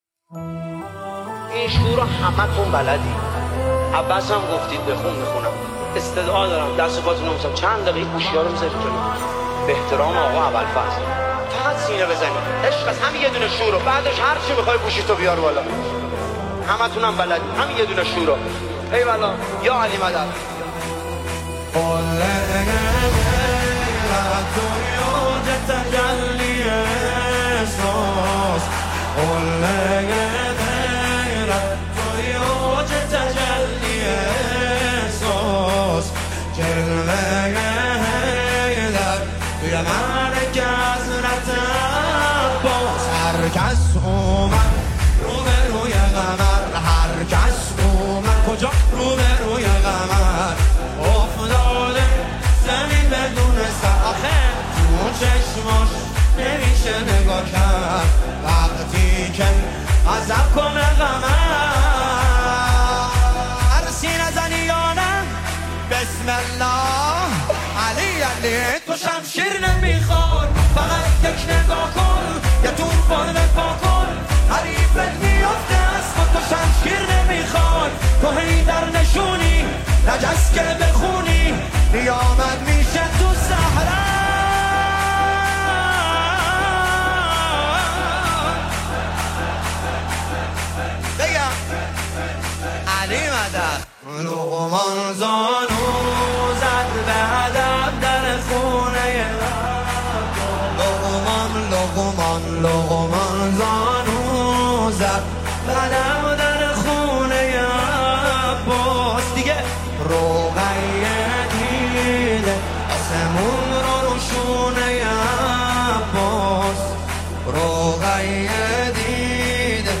یکی از مداحان است که در هیئت‌های مذهبی اصفهان مداحی می‌کند.